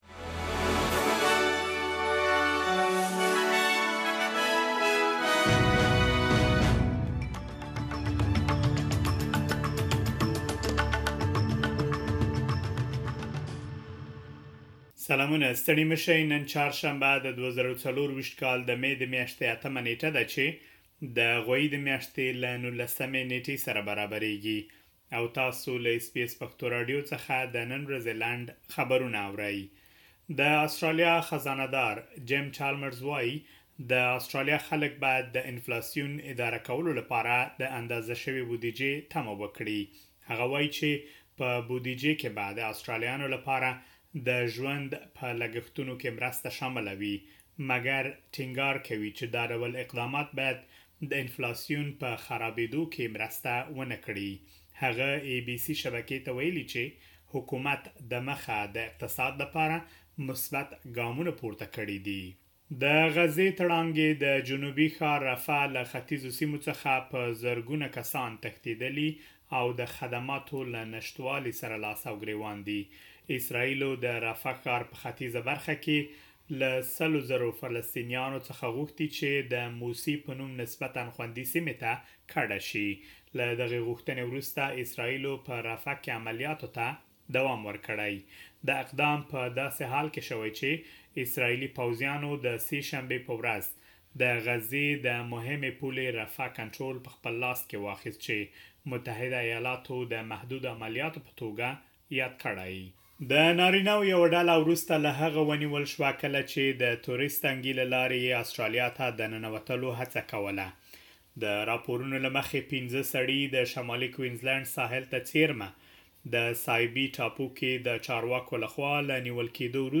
د اس بي اس پښتو راډیو د نن ورځې لنډ خبرونه|۸ مې ۲۰۲۴